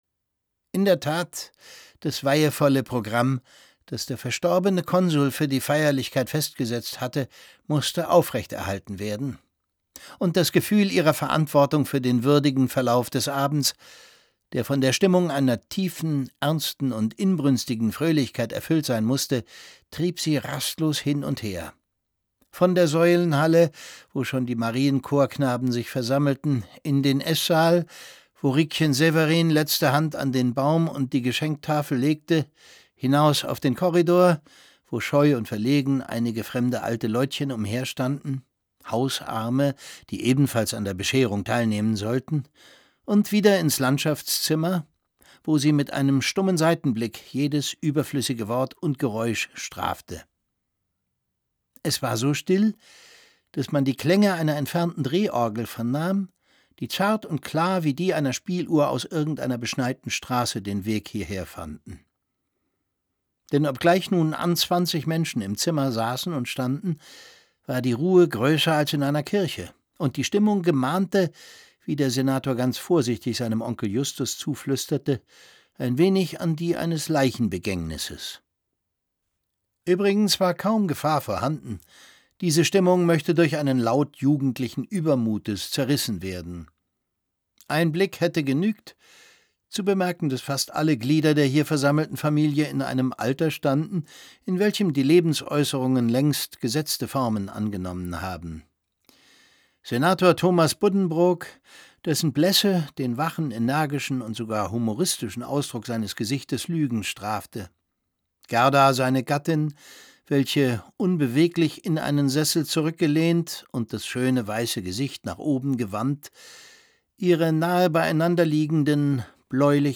Es liest Thomas Sarbacher.